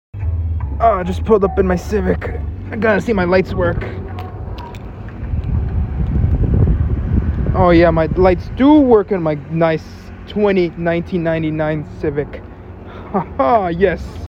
honda civic sound effects free download